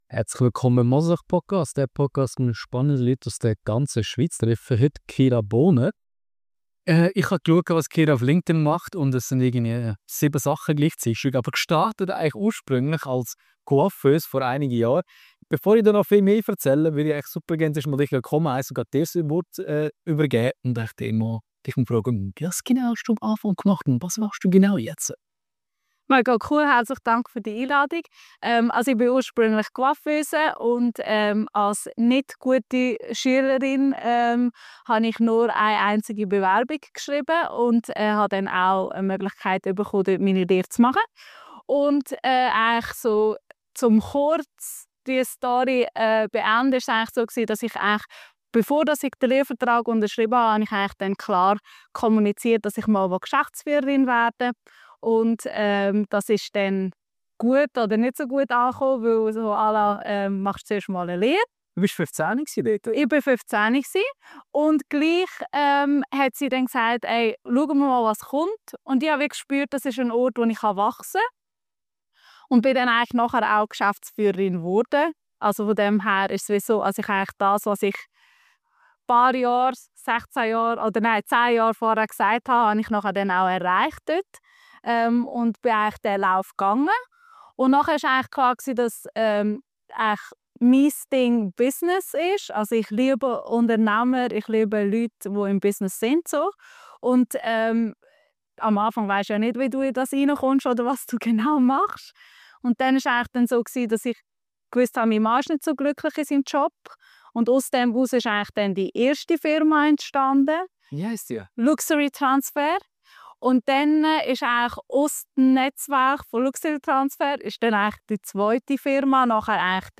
Ein ehrliches, energiegeladenes Gespräch über Arbeit, Leidenschaft, Erfolg – und warum Glück oft mit Mut beginnt.